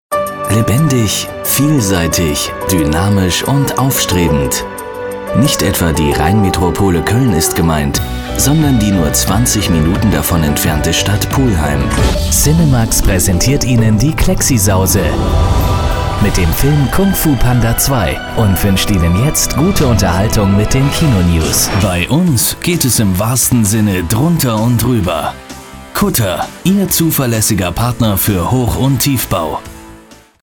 professionell, vielseitig und musikalisch
Kein Dialekt
Sprechprobe: Industrie (Muttersprache):